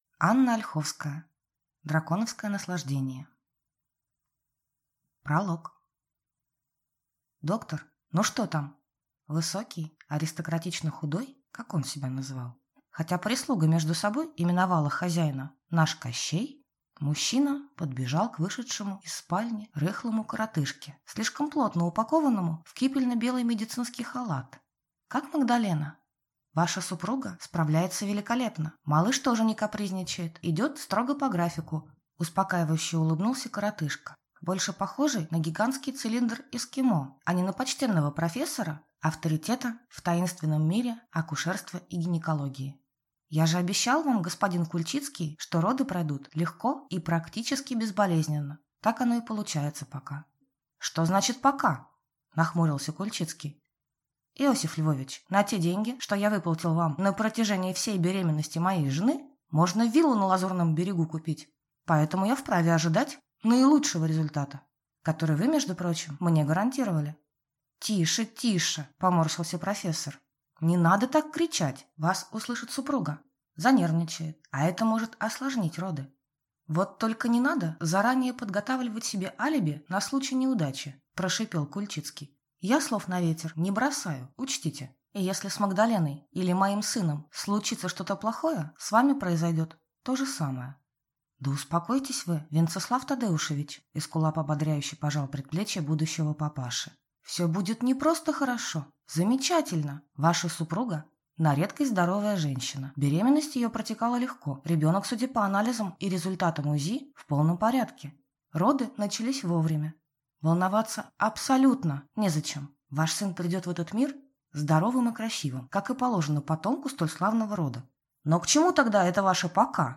Аудиокнига Драконовское наслаждение | Библиотека аудиокниг